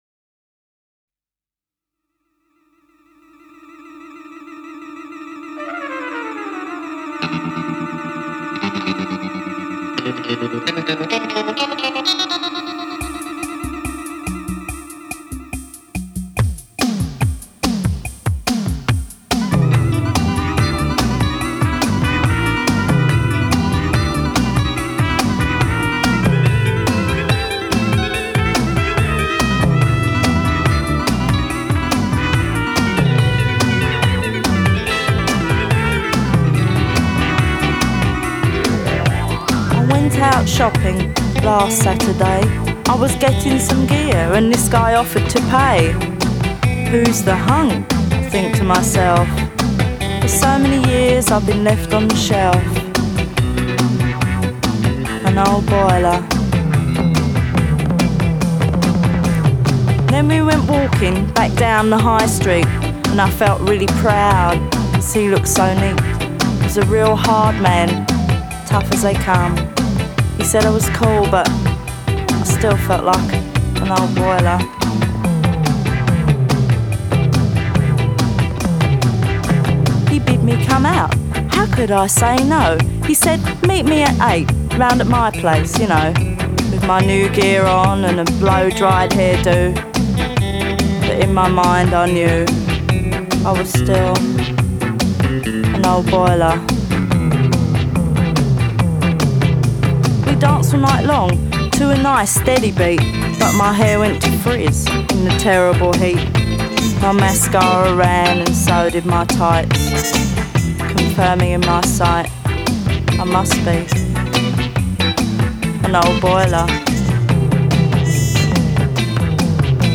Those screams as it ends….